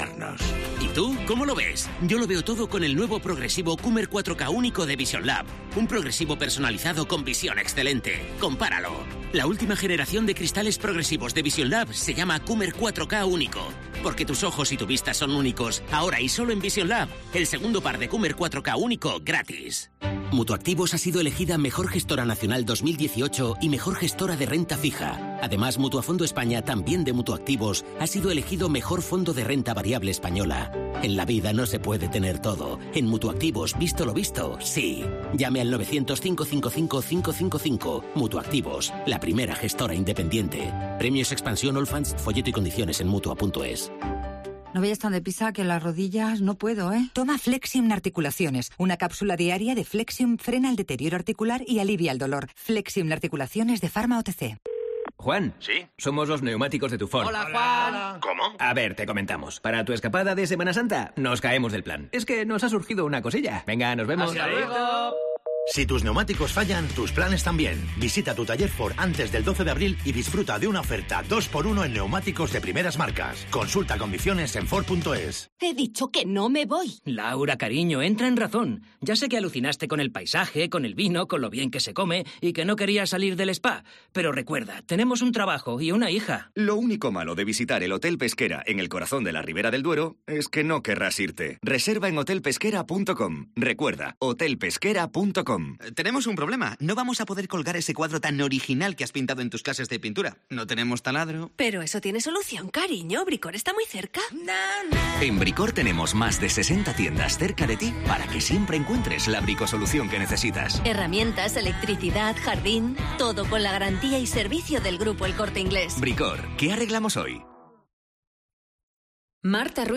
Informativo Mediodía 12 abril 14:50h